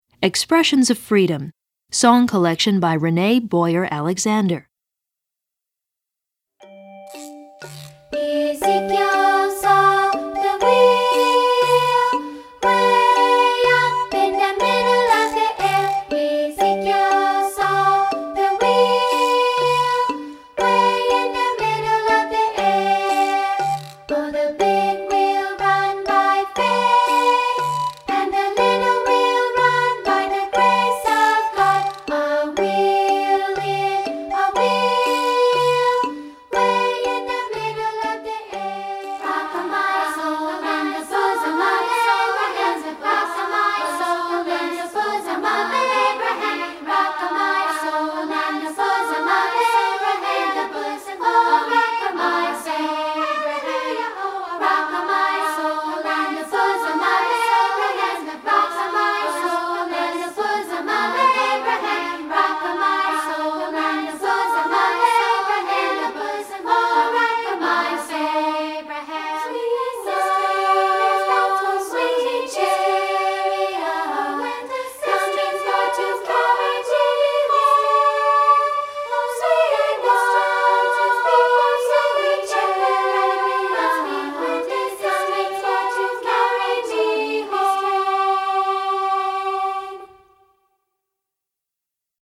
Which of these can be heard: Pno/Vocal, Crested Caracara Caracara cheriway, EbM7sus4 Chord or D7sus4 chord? Pno/Vocal